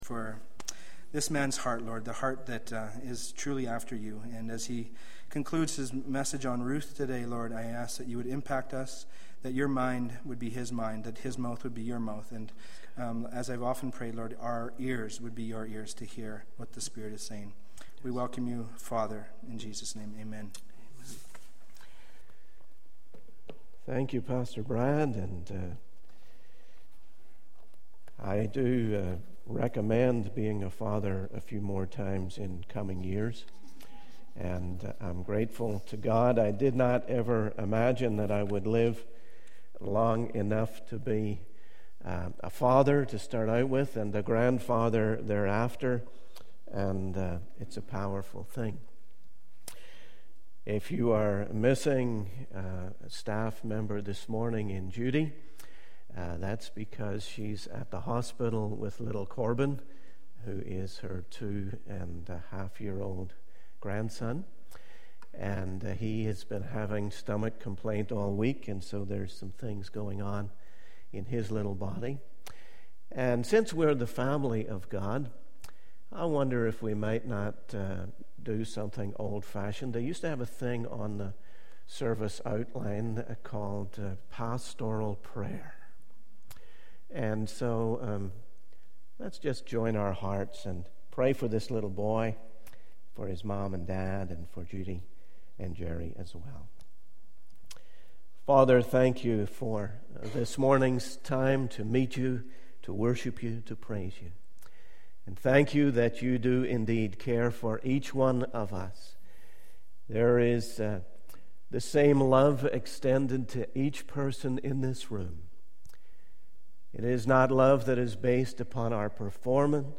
In this sermon, the preacher emphasizes the importance of having a godly heritage and how it can still happen today. Despite the challenges and distractions of the world, the preacher encourages listeners to trust in God and allow Him to work in their lives. The preacher shares personal experiences and examples of how God's plan can lead to something good.